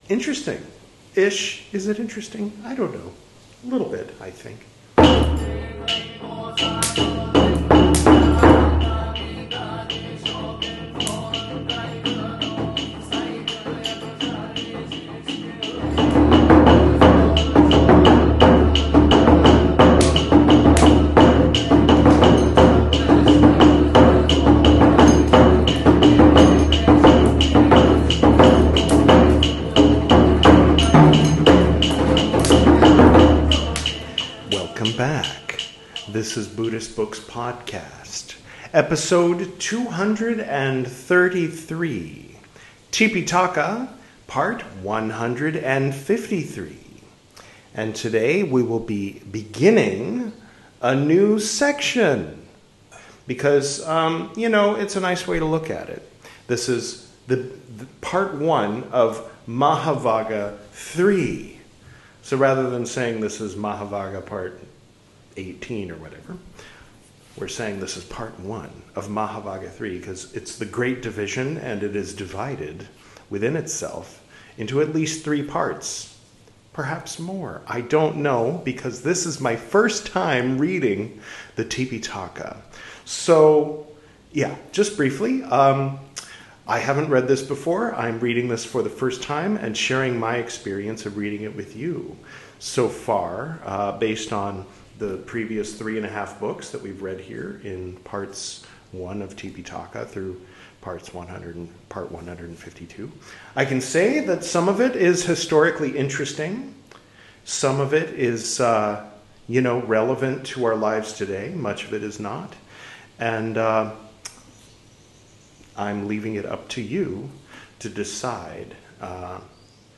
This is Part 153 of my recital of the 'Tipiṭaka,' the 'Three Baskets' of pre-sectarian Buddhism, as translated into English from the original Pali Language. In this episode, we'll begin reading 'Mahāvagga III,' from the 'Vinaya Piṭaka,' the first of the three 'Piṭaka,' or 'Baskets.'